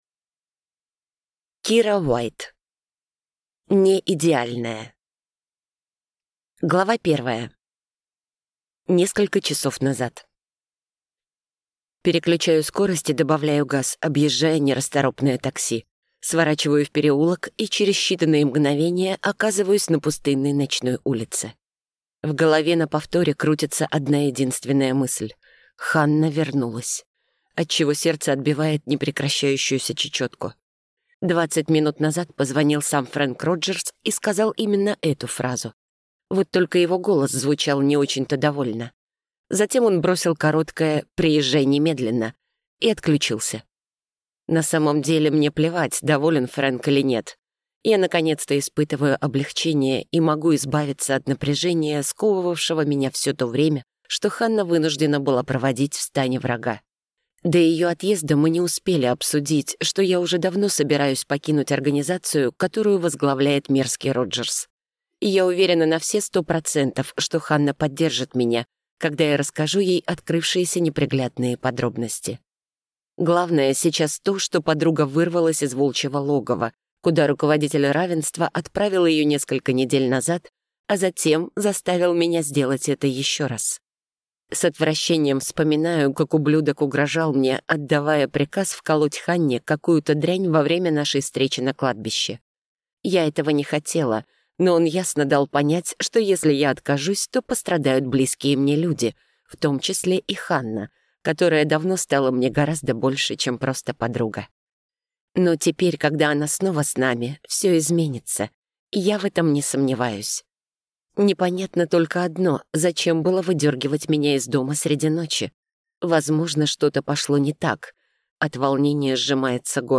Аудиокнига «Пропавший глаз».